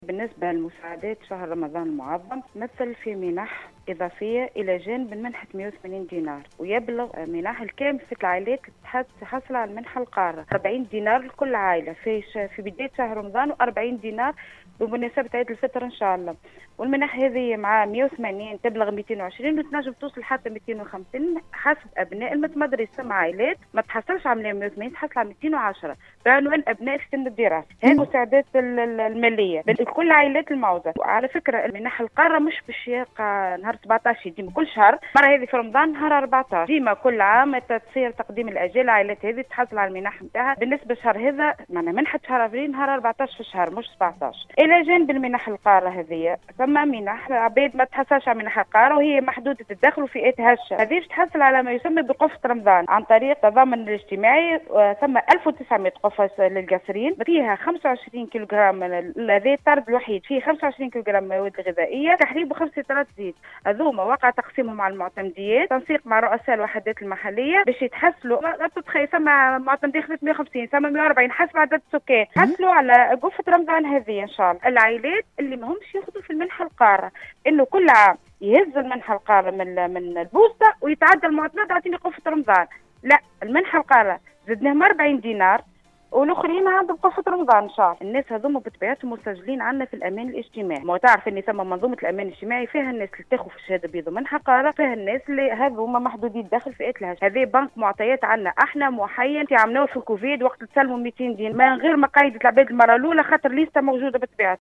أفادت المدير الجهوي للشّؤون الإجتماعيّة بالقصرين ” نرجس النصراوي ” أثناء تدخلها صباح اليوم الأحد 4 أفريل 2021  ببرنامج بو بجور ويكاند بإذاعة السيليوم أف أم،  أنّه  و بمناسبة شهر رمضان المعظّم سيتمّ صرف منح إضافيّة إلى جانب المنحة القارة المقدّرة بـ 180 دينار،  و هي تشمل كافة العائلات التي تتحصّل على المنحة القارة ، حيث ستصرف  40 دينار لكلّ عائلة في بداية شهر رمضان و 40 دينار بمناسبة عيد الفطر .